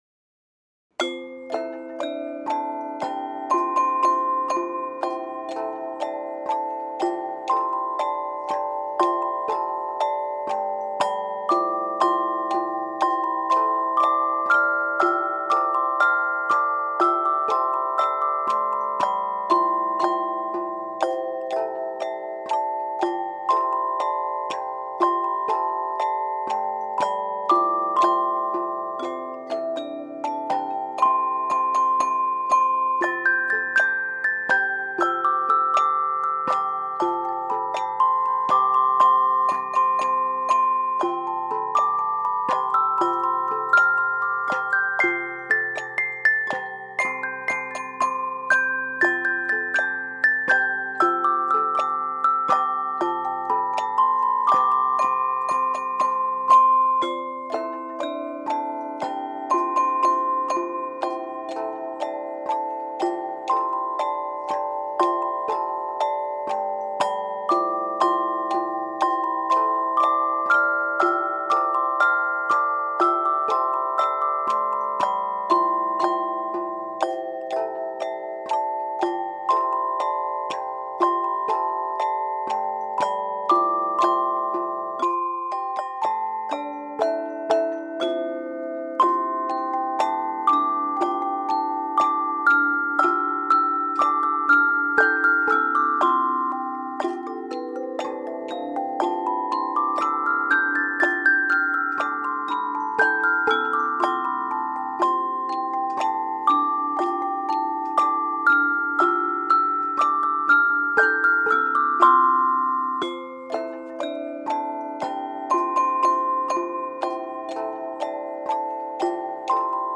Harmony in the GreenSpace for a live audience